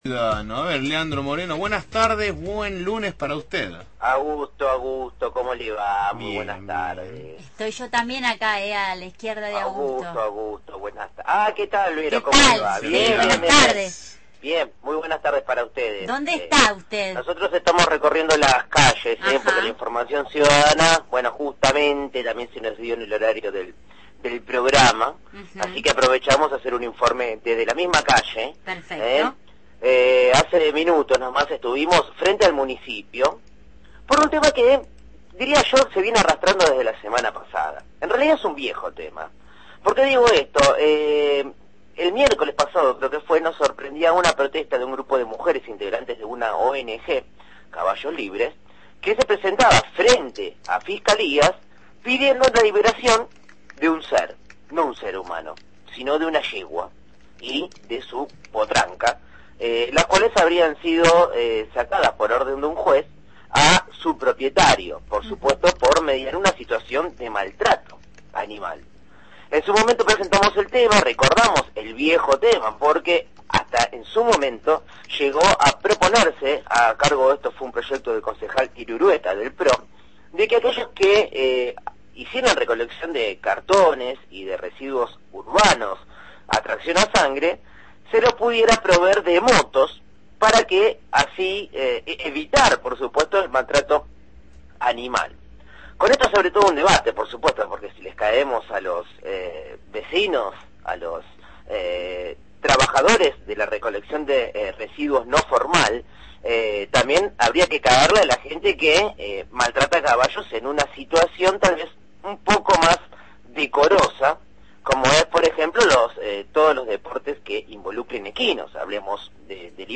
columna de periodismo ciudadano